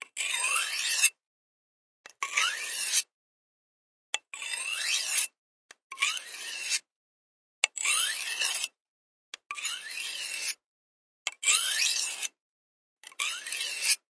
磨刀石
描述：用磨刀石研磨菜刀
Tag: 厨房 锐化 磨石